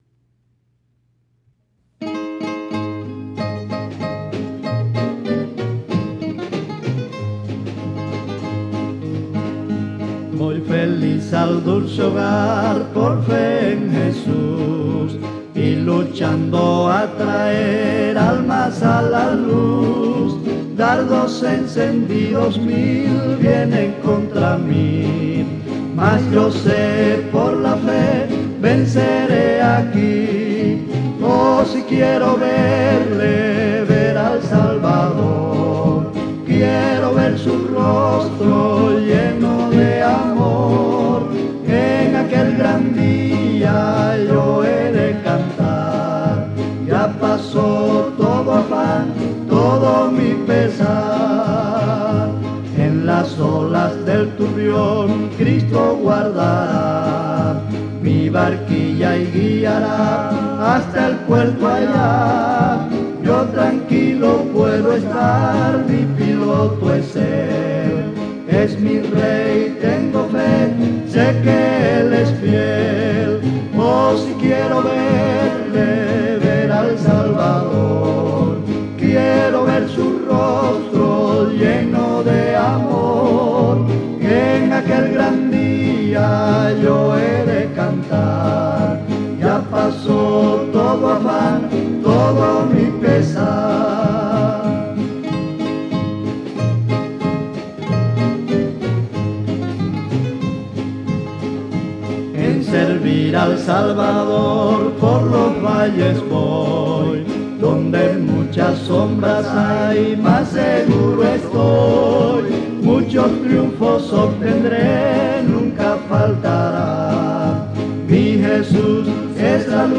Himno titulado